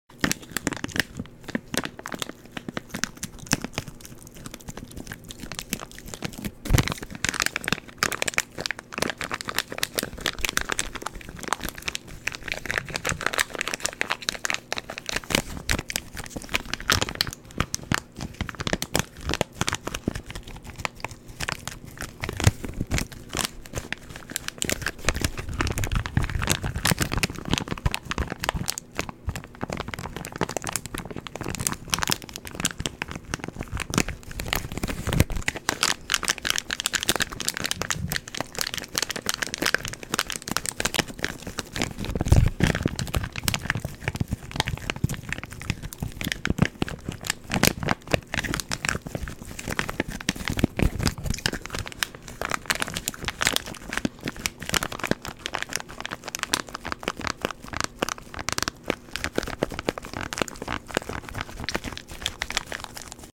ASMR Plastic Bag Biting Mouth Sound Effects Free Download